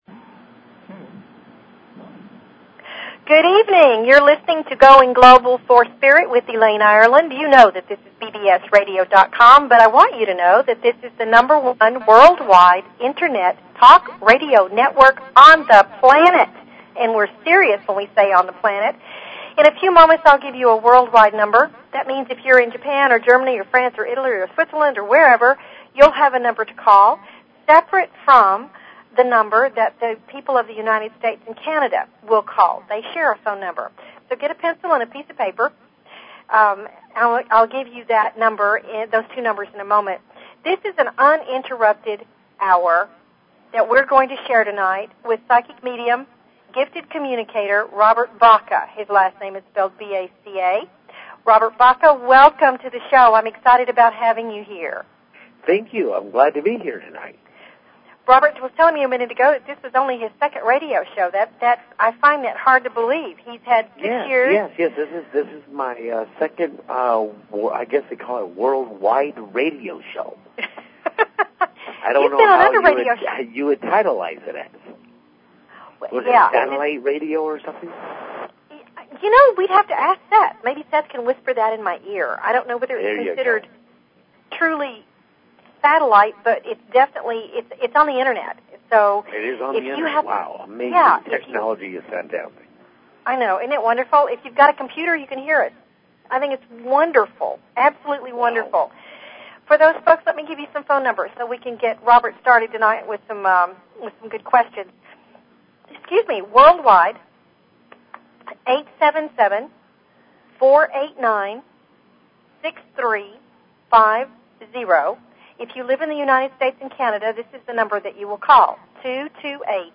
Talk Show Episode, Audio Podcast, Going_Global_for_Spirit and Courtesy of BBS Radio on , show guests , about , categorized as
They invite you to call in with your questions and comments about everything metaphysical and spiritual!"